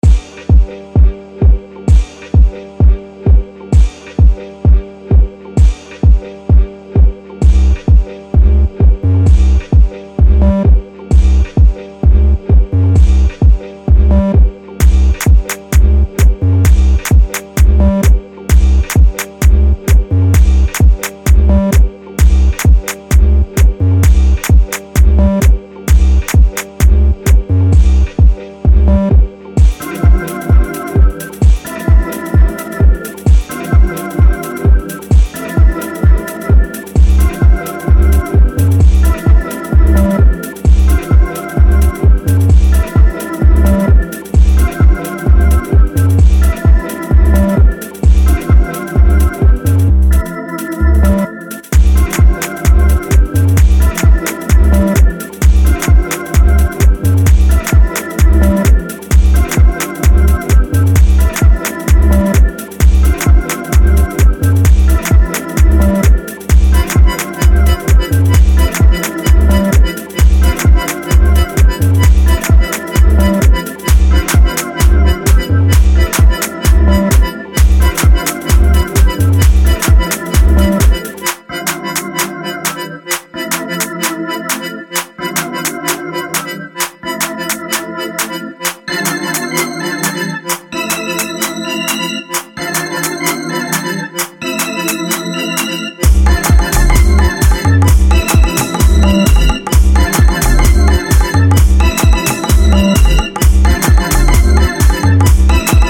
Mais club-ready do que o seu último disco
afro house